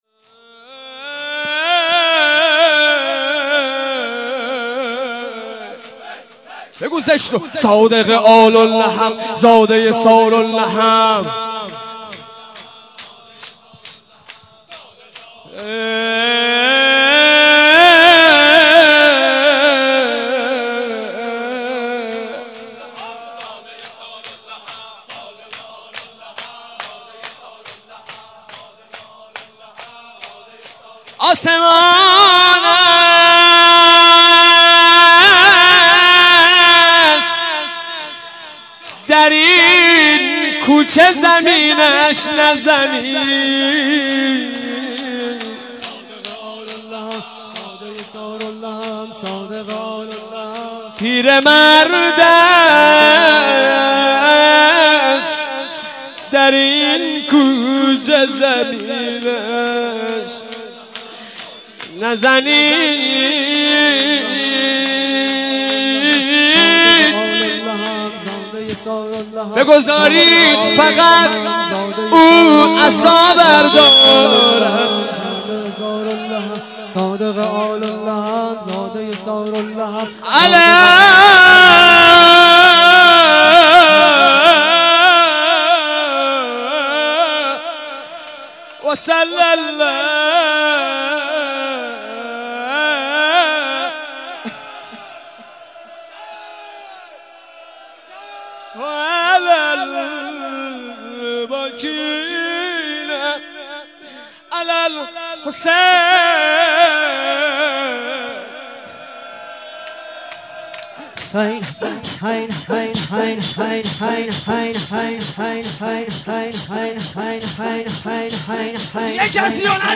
زمینه2